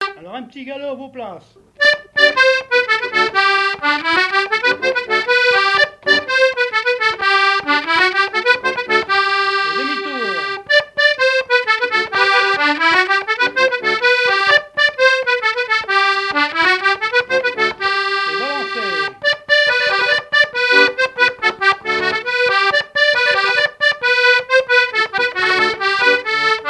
Mémoires et Patrimoines vivants - RaddO est une base de données d'archives iconographiques et sonores.
danse : quadrille : petit galop
Pièce musicale inédite